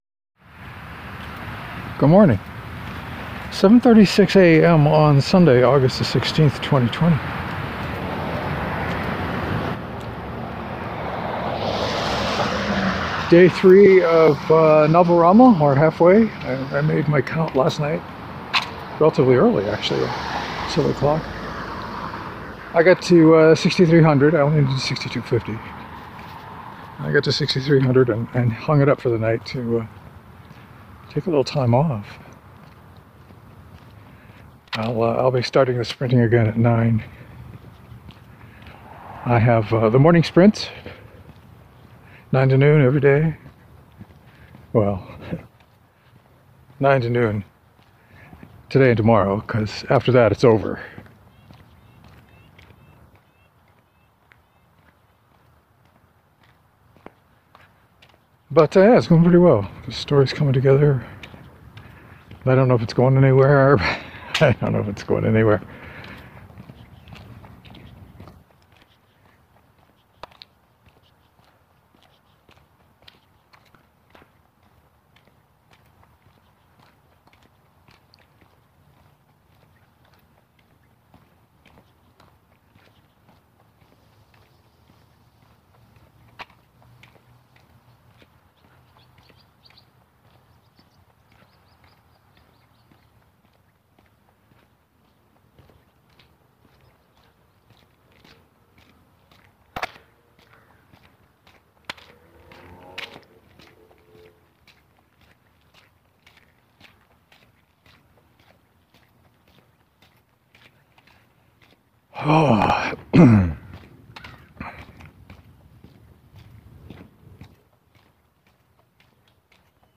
I talked about NovelRama but there’s a lot of footsteps in this one.